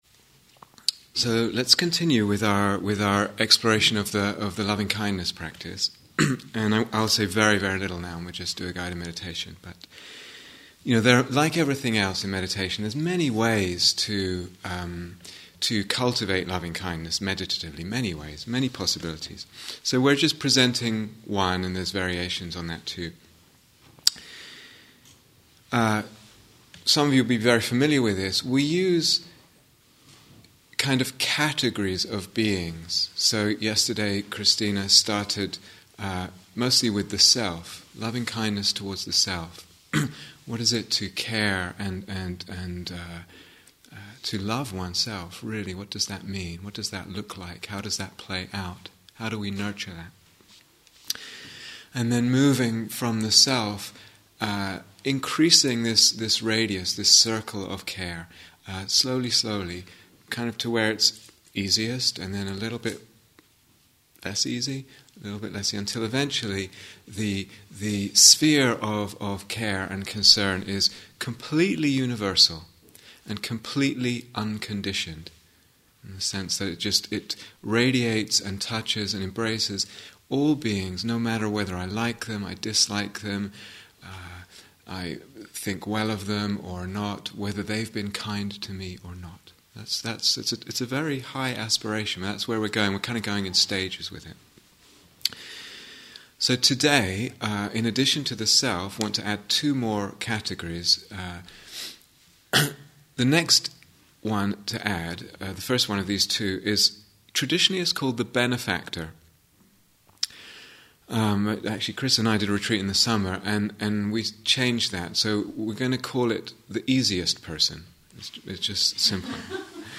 Mettā Instructions and Guided Meditation (Mettā for Friend and Benefactor)